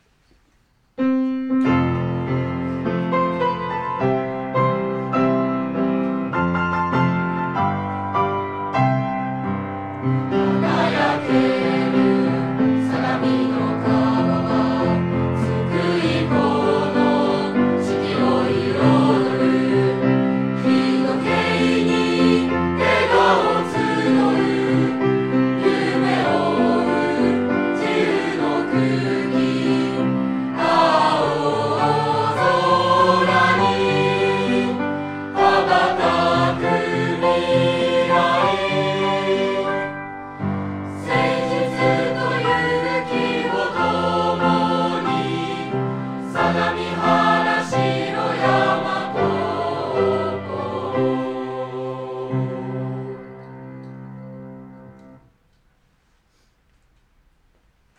校歌
生徒による演奏は